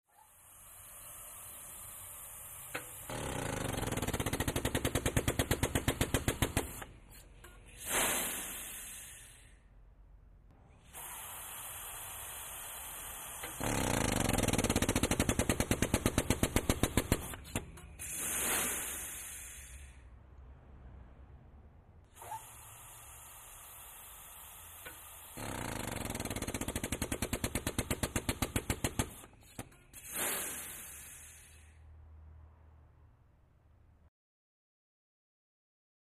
Hydraulic Press, W Compressed Air Release Sputter, Hissing.